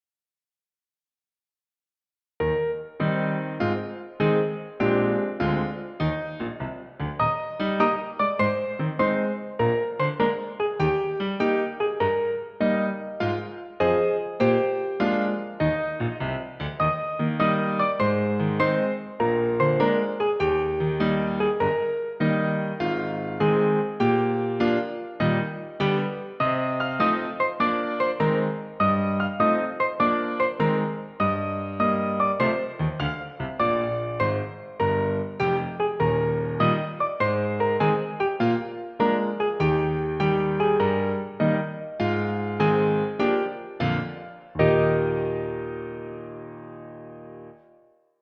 Piano accompaniment
Musical Period 19th century British, Australian, American
Tempo 100
Rhythm March
Meter 4/4